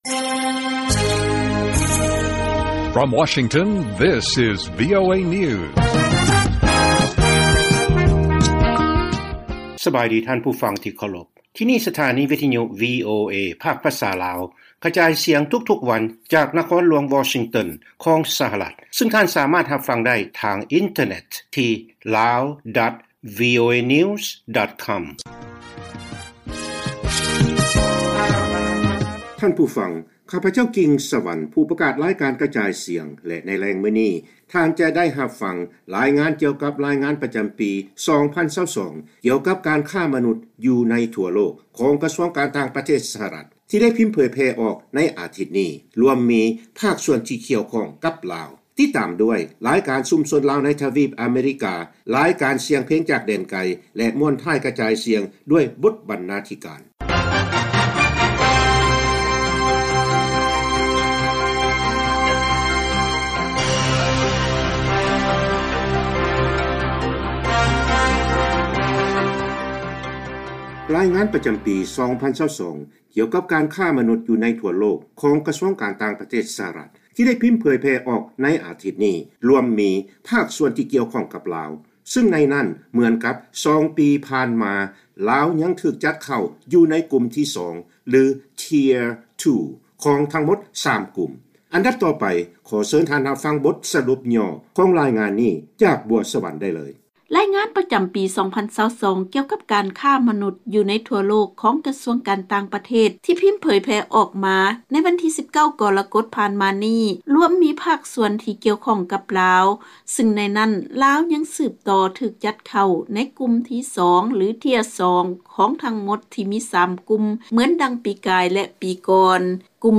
ລາຍການກະຈາຍສຽງຂອງວີໂອເອລາວ: ເຮົາມີລາຍງານການຄ້າມະນຸດ ຂອງກະຊວງການຕ່າງປະເທດ ມາສະເໜີທ່ານ